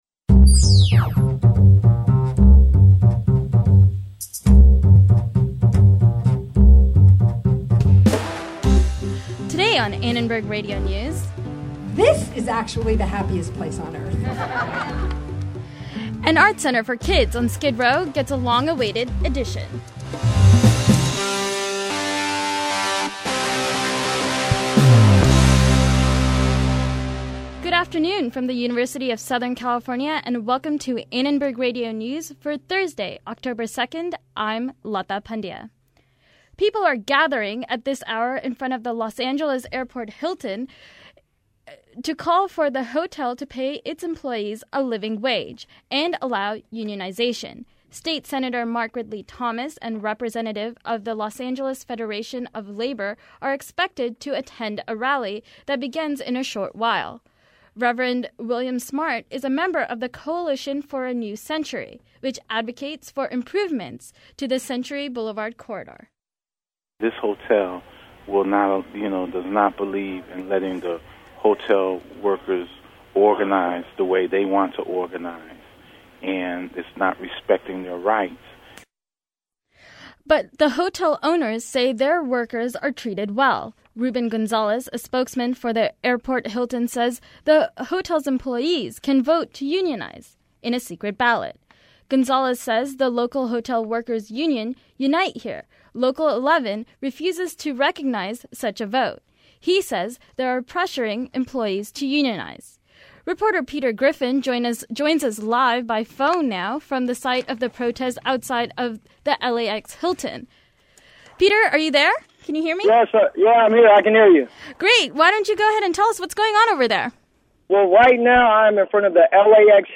A labor protest is threatening to shut down streets near the LA airport and we covered it live from on the scene.